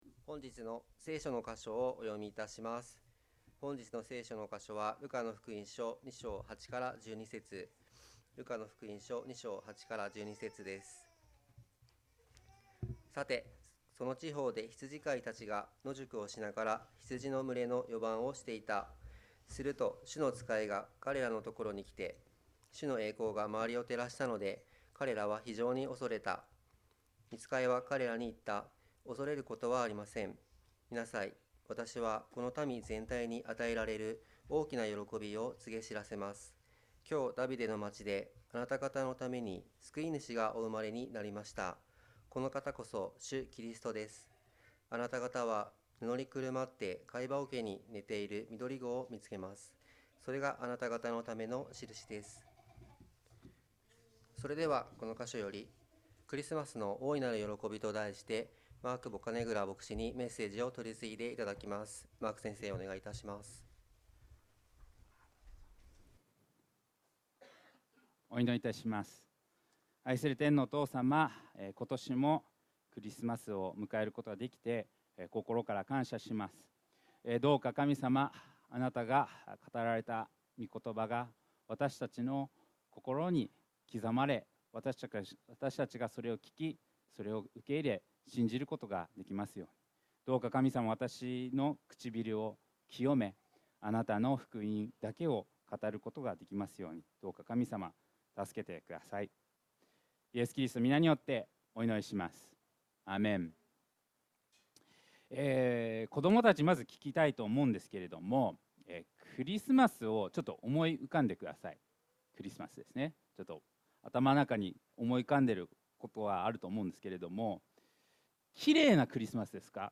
2024年12月22日礼拝 説教 「クリスマスの大いなる喜び」 – 海浜幕張めぐみ教会 – Kaihin Makuhari Grace Church